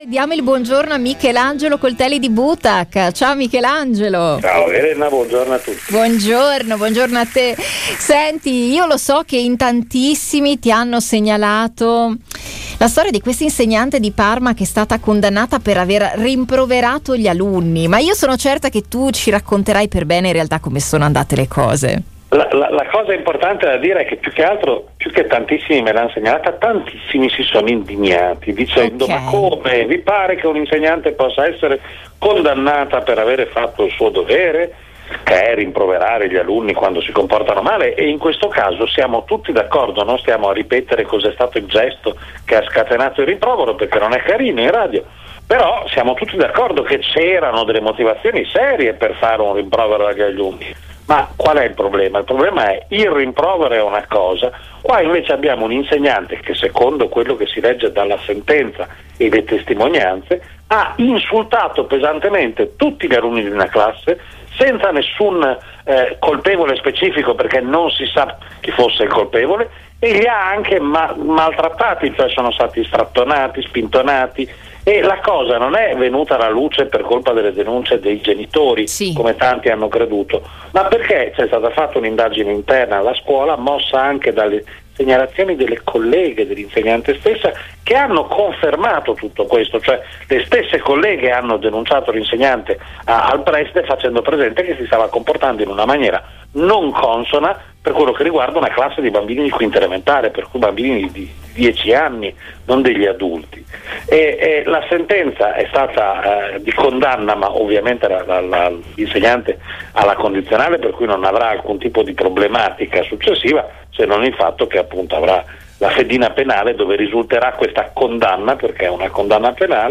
Ecco l’intervista: